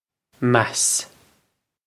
meas mass
This is an approximate phonetic pronunciation of the phrase.